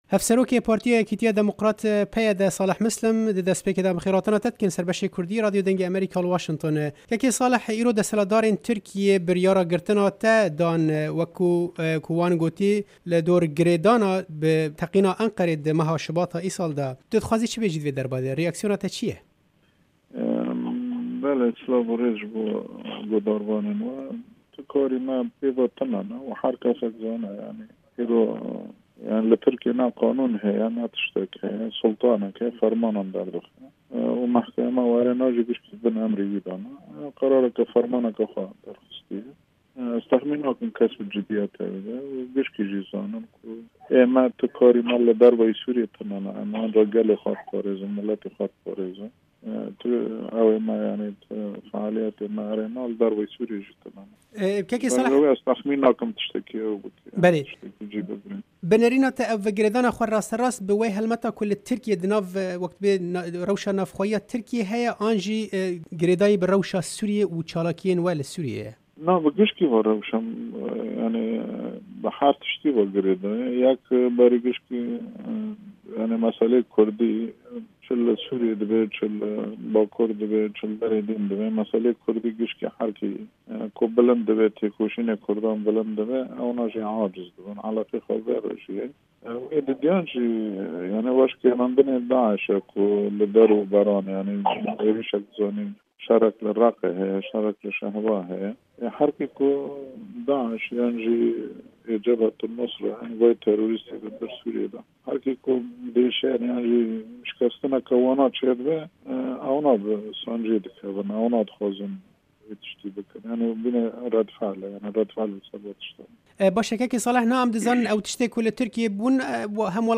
Hevpeyvîn li gel Salih Mislim Hevserokê PYD